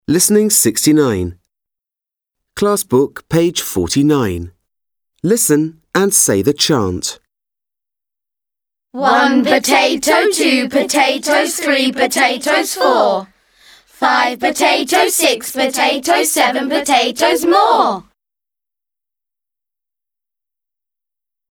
Listen to the chant. Na koniec zachęcam do wysłuchania wyliczanki w wykonaniu brytyjskich dzieci.